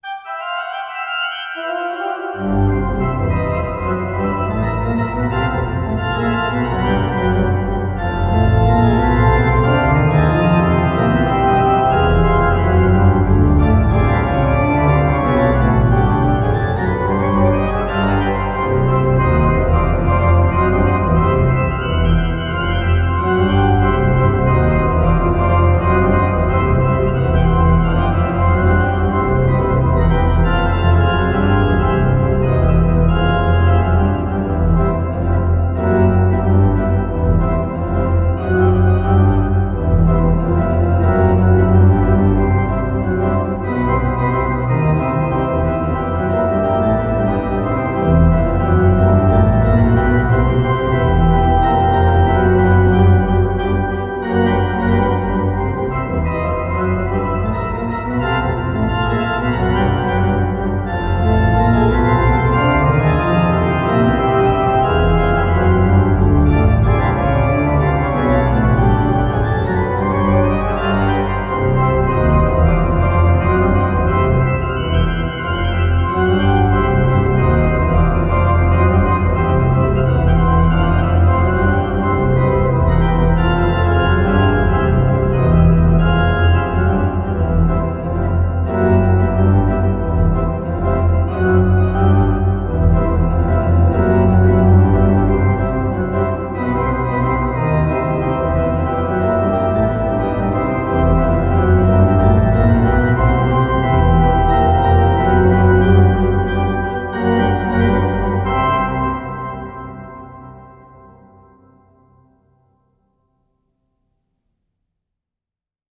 pipe_organ